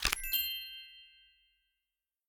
card_foundation.m4a